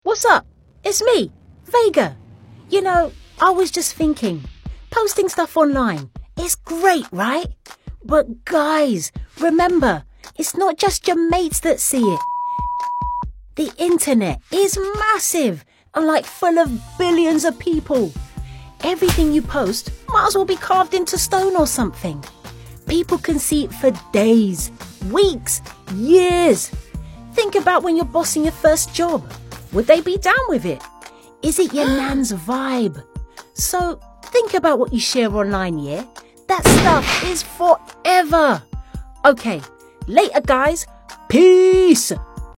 English (British)
Friendly, Warm, Natural, Commercial, Corporate